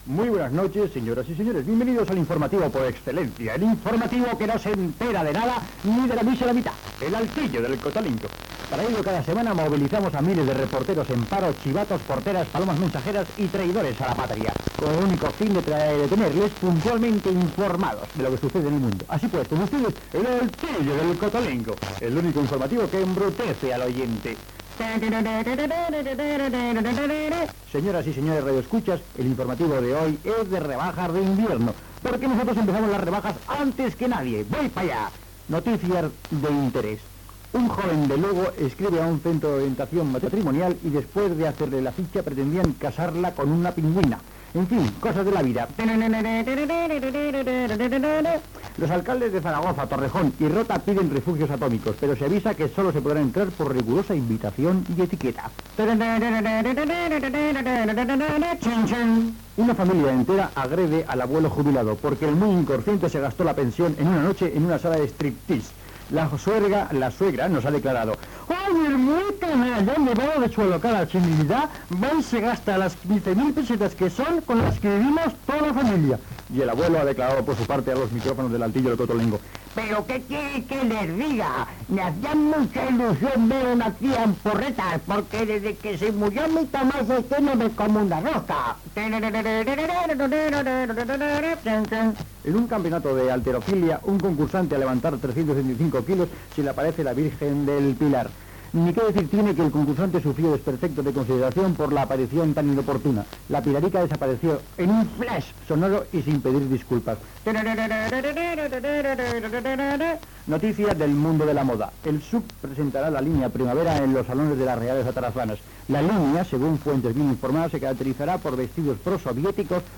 "El altillo del cotolengo", informatiu humorístic
FM